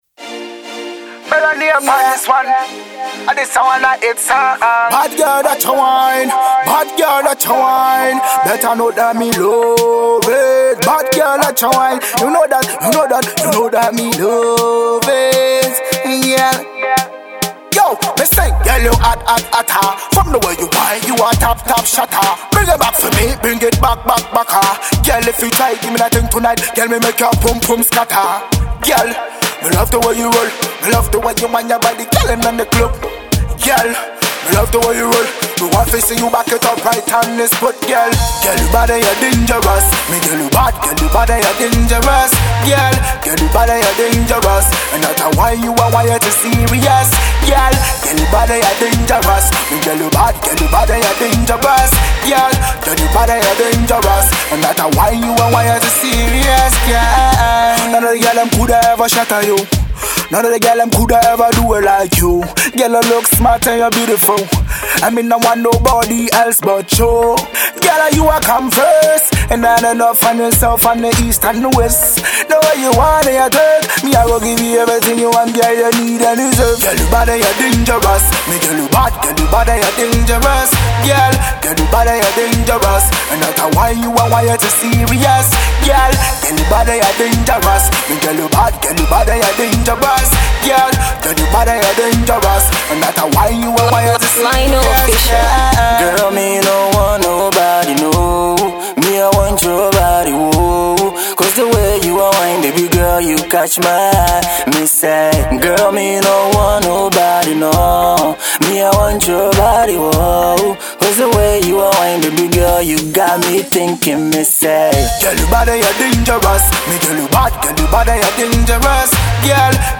Reggae/Dancehall